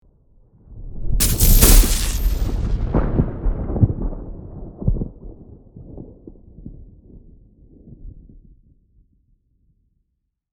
Electrifying Lightning Strike 2 Sound Effect Download | Gfx Sounds
Electrifying-lightning-strike-2.mp3